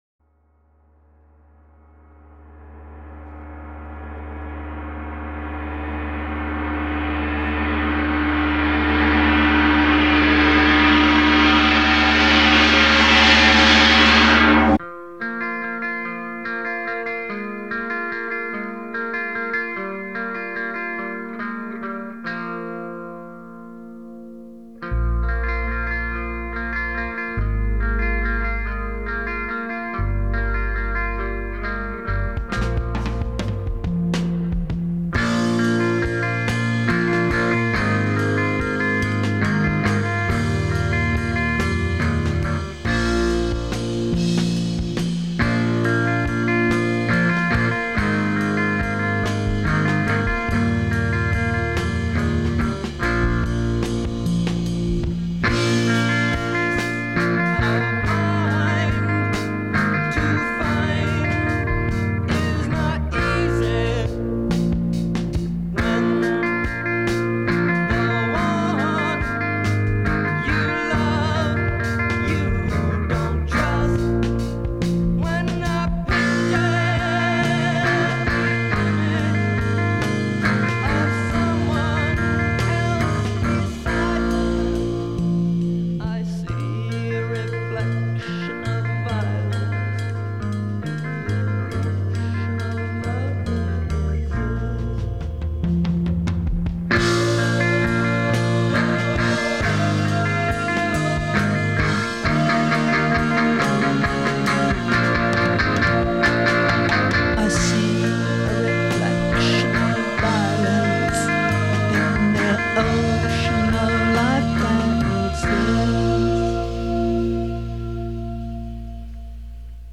سبک موسیقی سایکدلیک راک
Psychedelic Rock